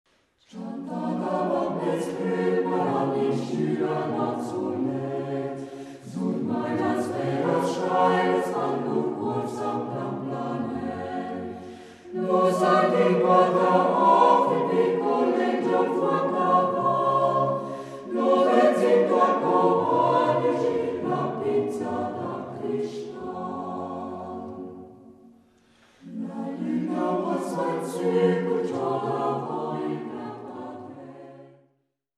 La corale